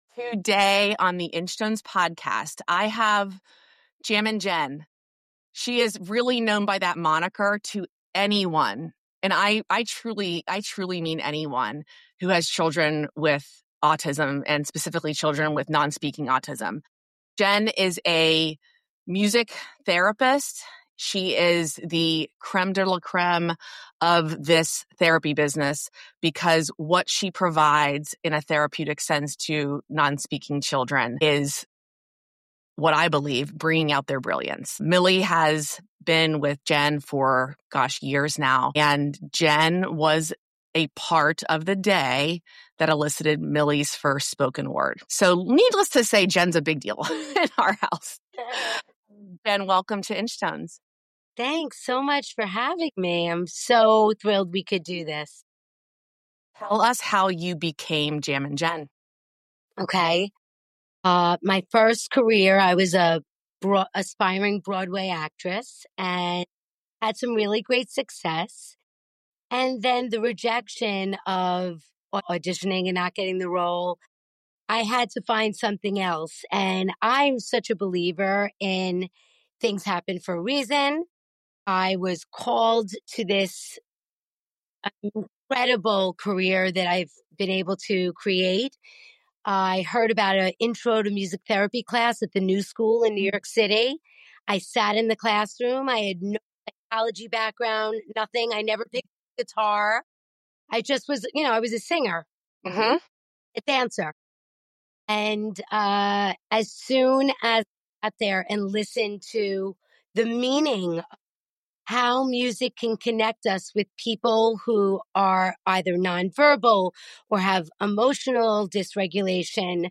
The conversation explores how music can unlock connection, the delicate process of building trust through sound and touch, and the critical role of individualized therapy.